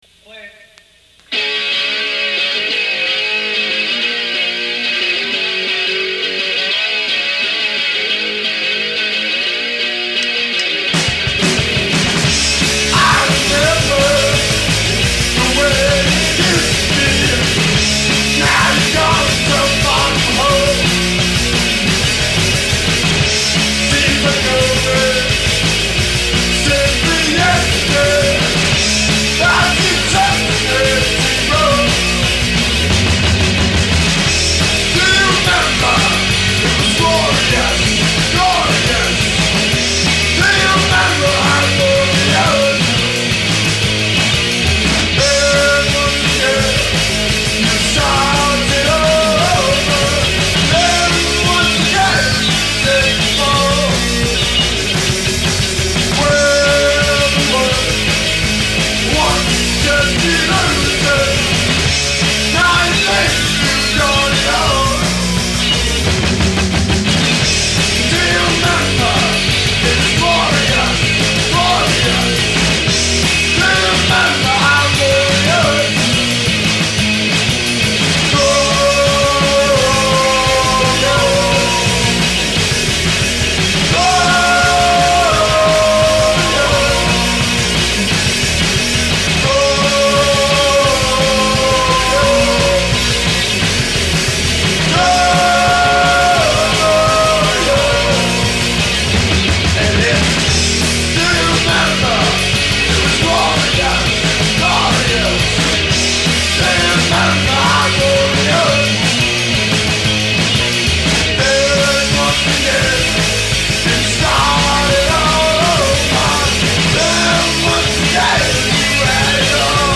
These songs are from their demo tape.